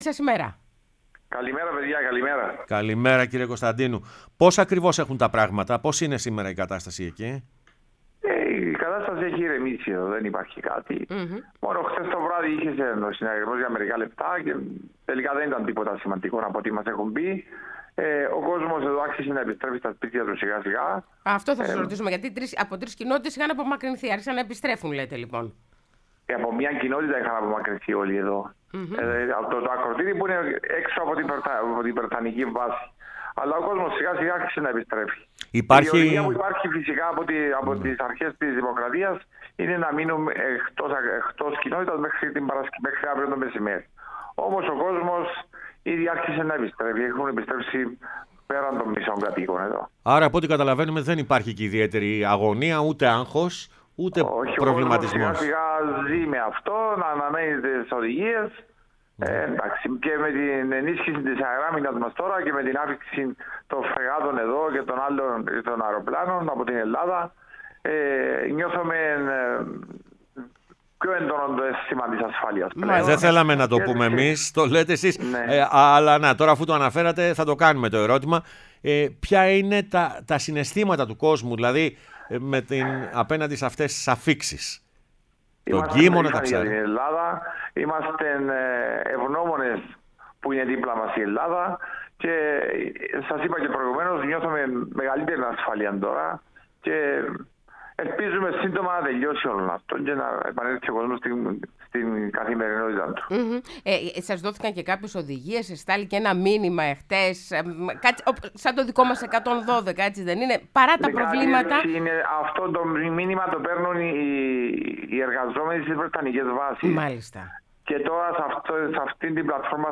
Ο Γιώργος Κωνσταντίνου, αντιδήμαρχος Ακρωτηρίου Κύπρου, μίλησε στην εκπομπή Πρωινές Διαδρομές